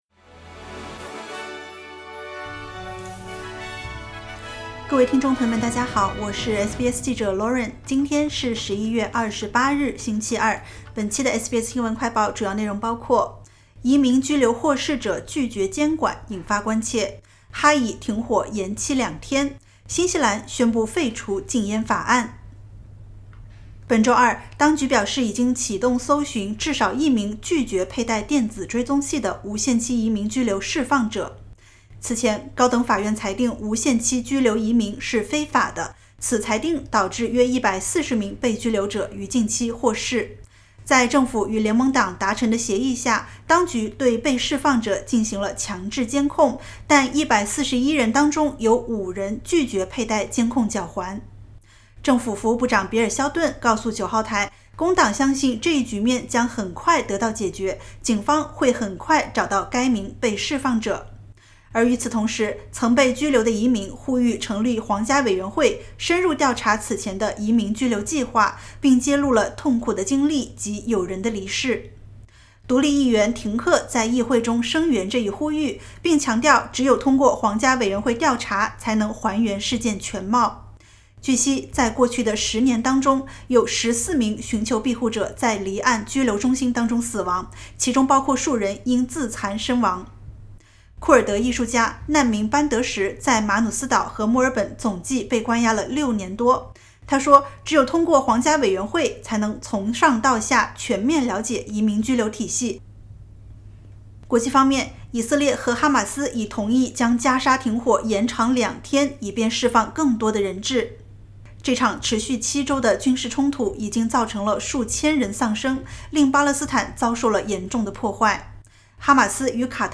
【SBS新闻快报】移民获释者拒绝监管引关切 呼吁皇家委员会全面审查